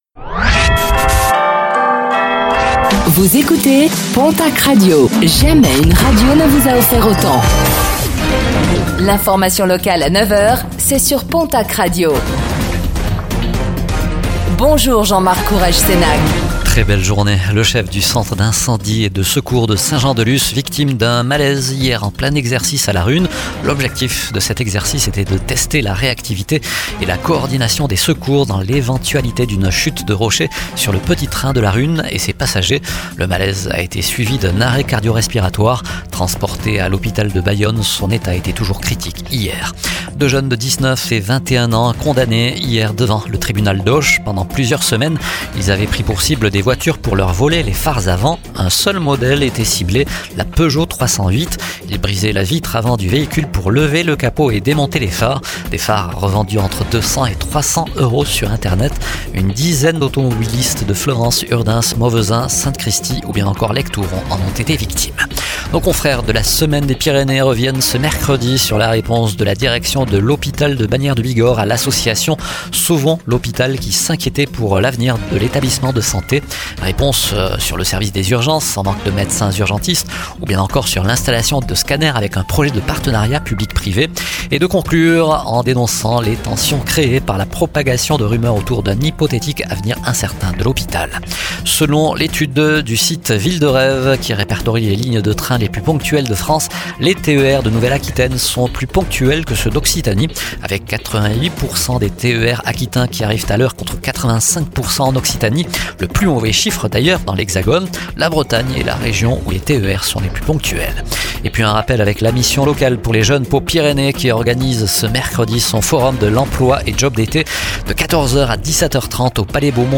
Infos | Mercredi 26 mars 2025